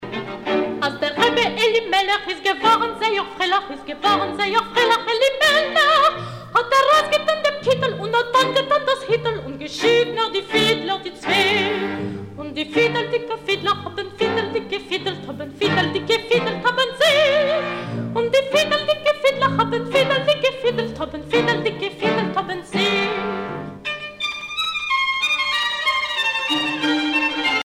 Cantilations bibliques